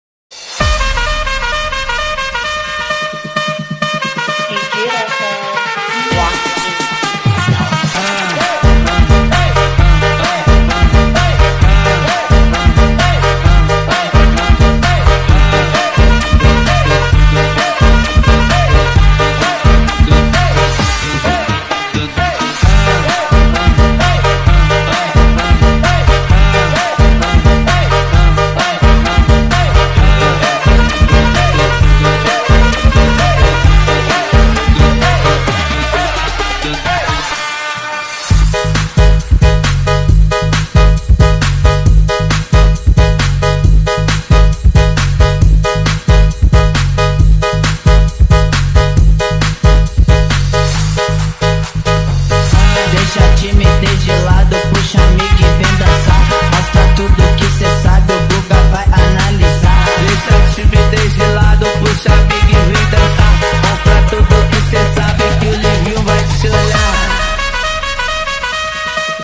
Bass nya NENDANG, suaranya BIKIN NAGIH! sound effects free download